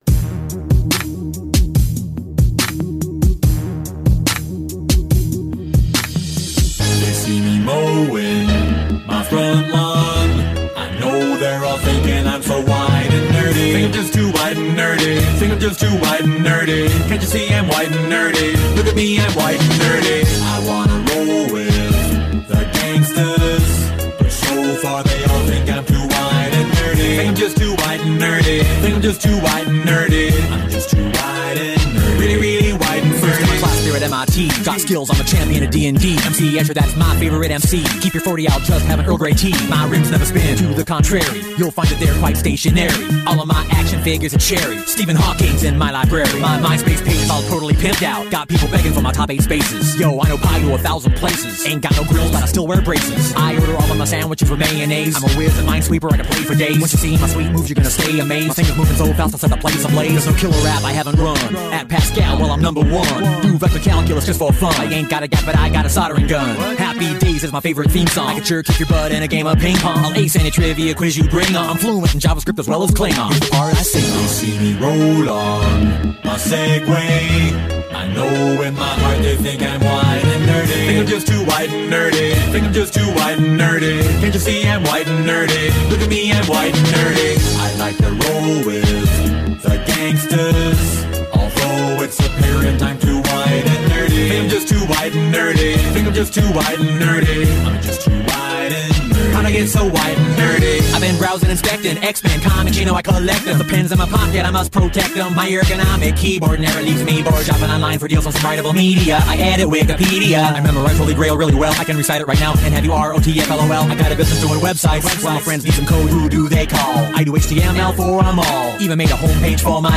Electronic Folk Hip Hop International